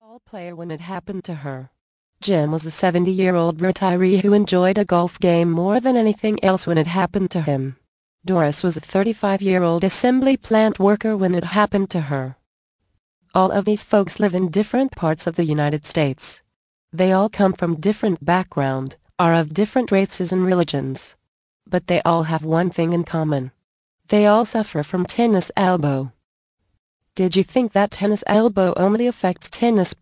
Cure Tennis Elbow without Surgery mp3 Audio Book + FREE Gift